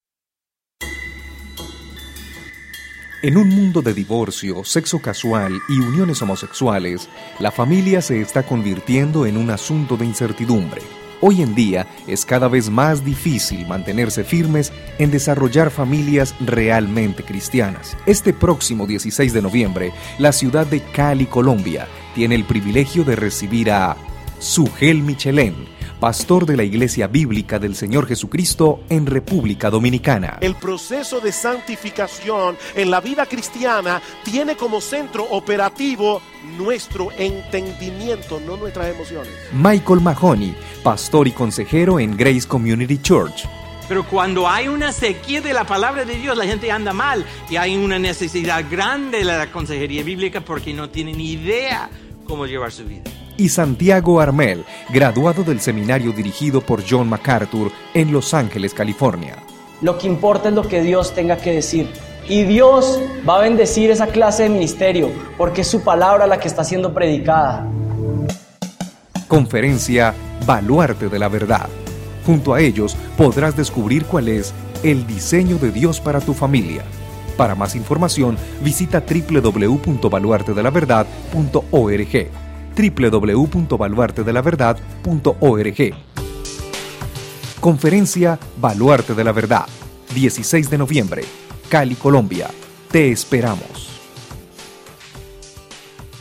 Una voz cálida y profesional
kolumbianisch
Sprechprobe: Werbung (Muttersprache):